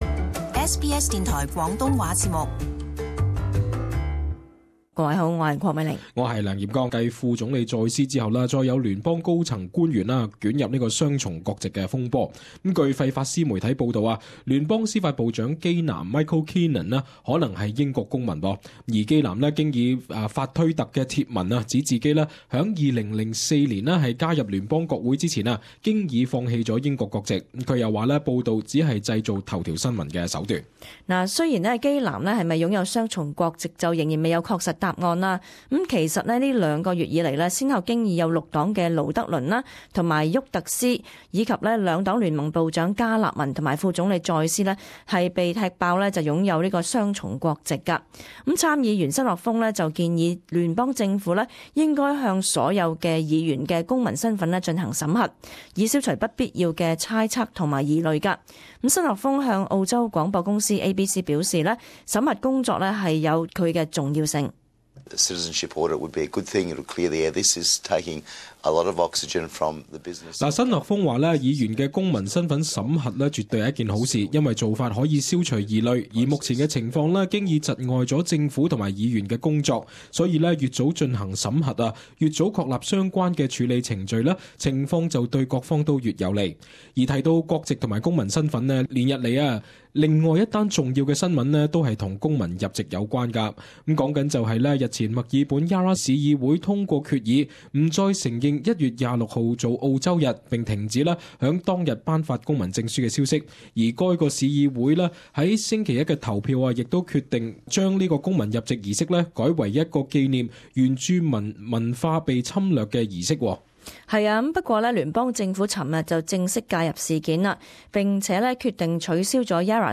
【時事報導】聯邦政府介入Yarra市議會不承認澳洲日決議